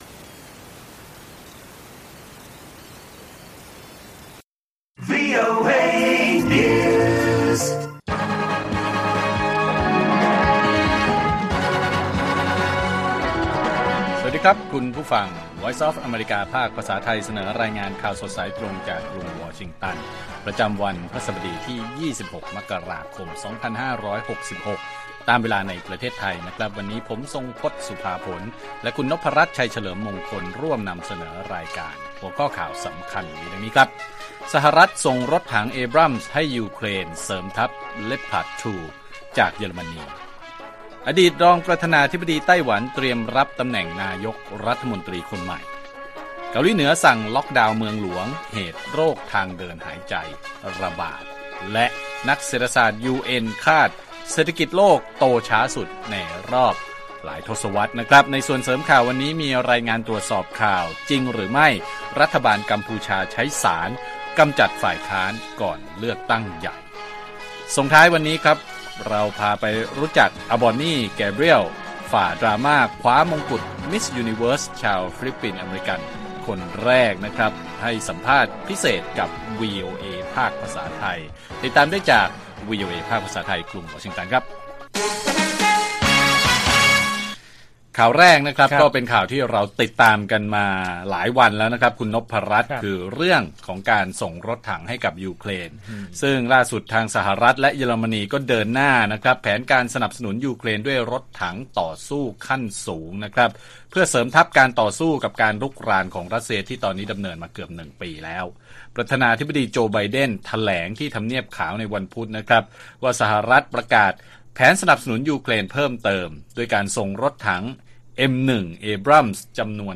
ข่าวสดสายตรงจากวีโอเอ ไทย พฤหัสฯ 26 มกราคม 2566